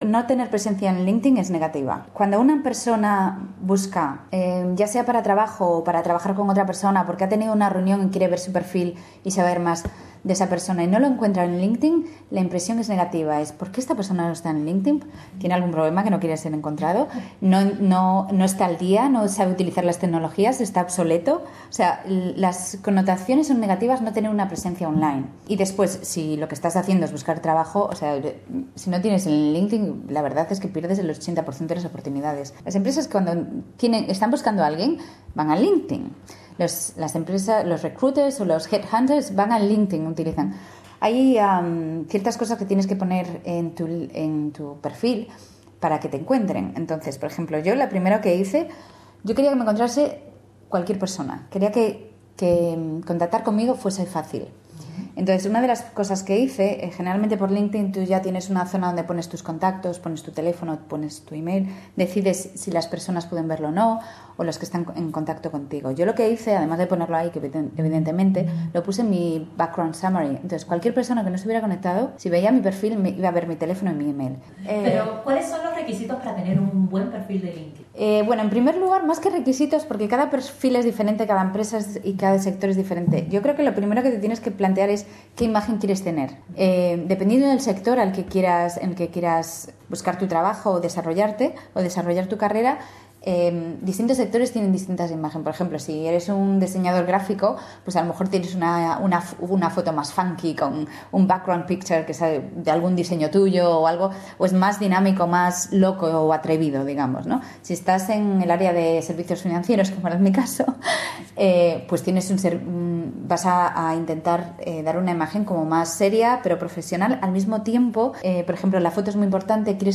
Para esclarecer estas dudas conversamos con la experta en mercadeo y comunicaciones